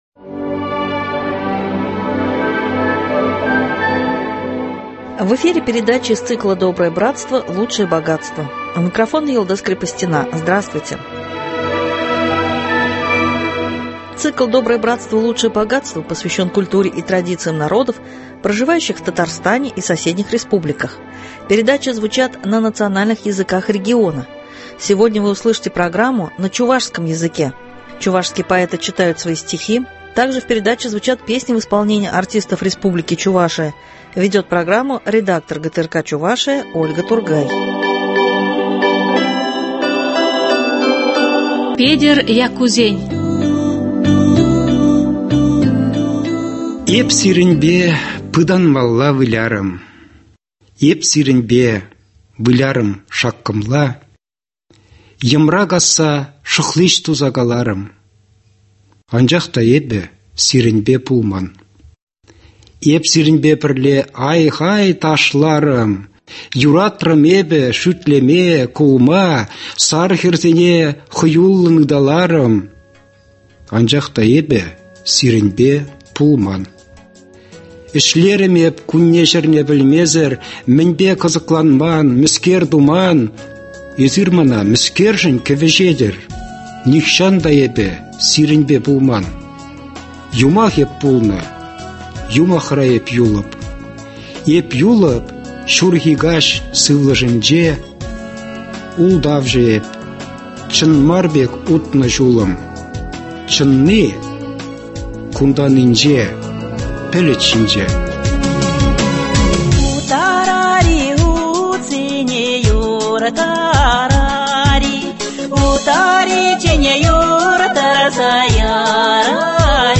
Чувашские поэты читают свои стихи, также в передаче звучат песни в исполнении артистов республики Чувашия.